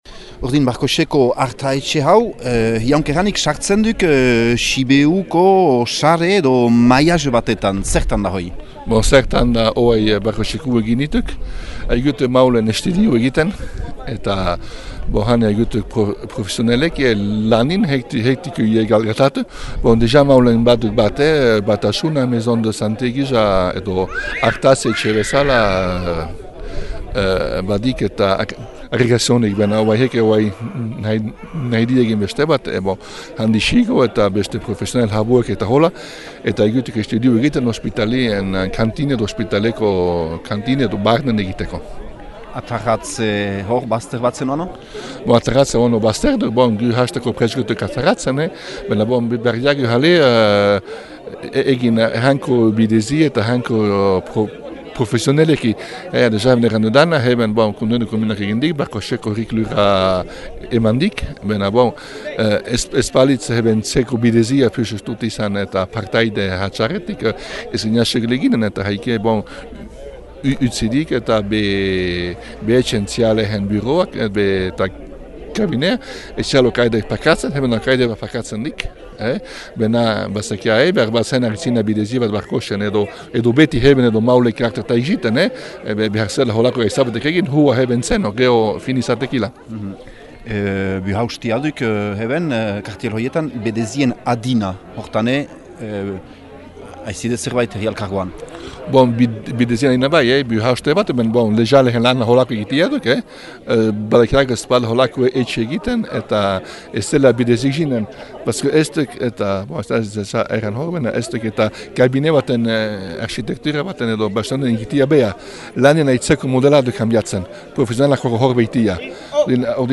Entzün Dominika Boscq Xiberoa Herri Alkargoako bürüa :